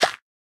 Minecraft Version Minecraft Version snapshot Latest Release | Latest Snapshot snapshot / assets / minecraft / sounds / block / composter / empty3.ogg Compare With Compare With Latest Release | Latest Snapshot